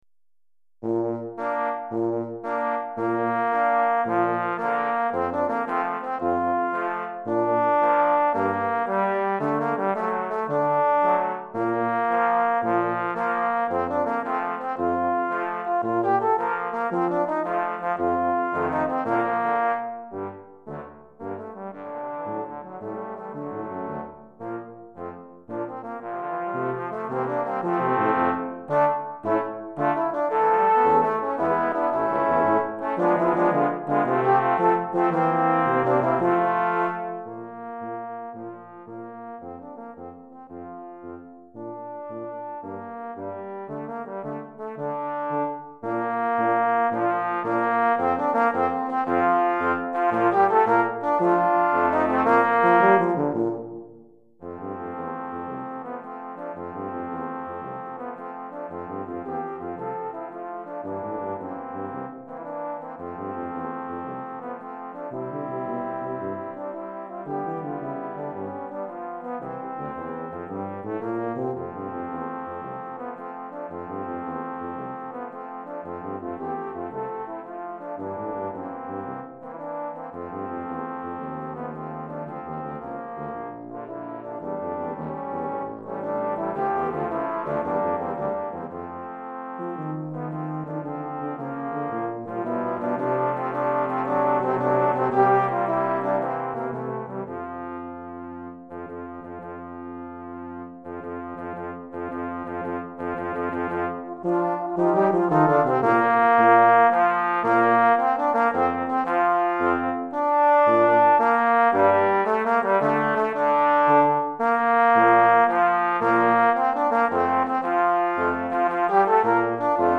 3 Trombones et Trombone Basse